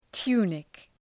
Προφορά
{‘tu:nık}
tunic.mp3